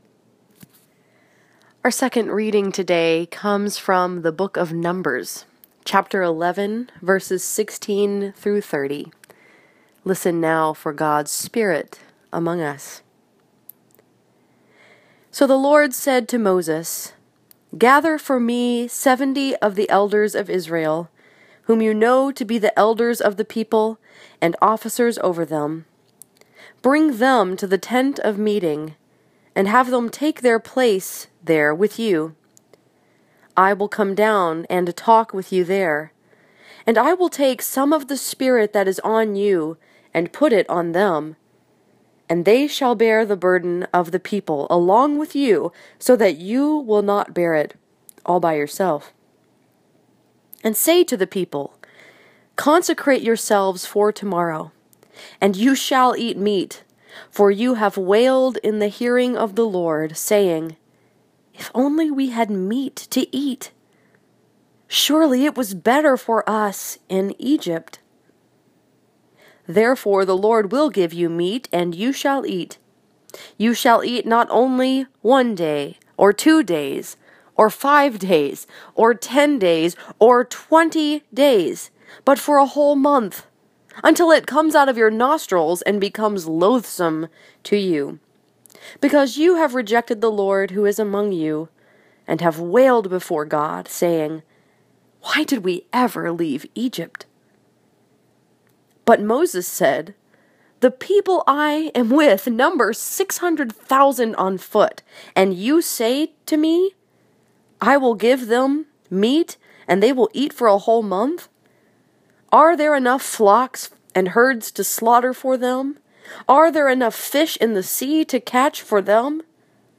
This morning, I had the privilege to speak at Northside Presbyterian Church in Ann Arbor, Michigan.